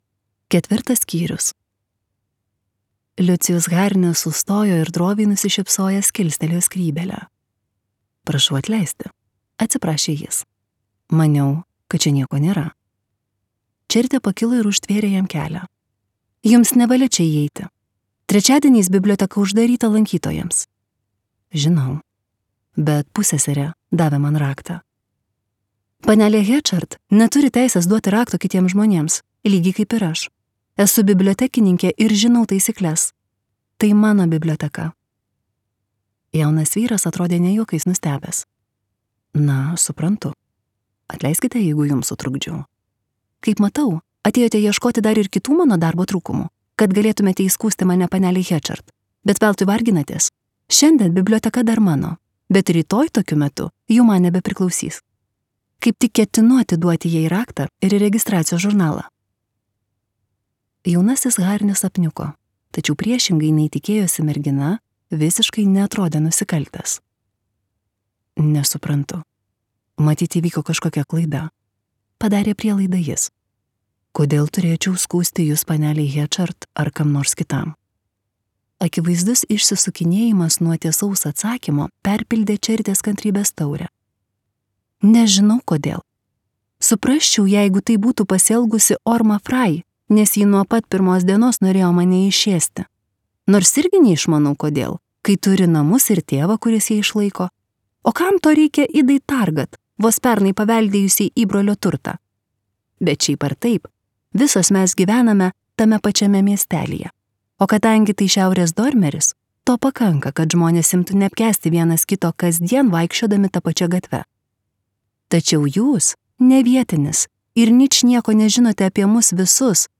Audioknyga „Vasara“ – tai Edith Wharton romanas apie jaunos moters meilės ir savęs pažinimo kelionę mažame Naujosios Anglijos miestelyje.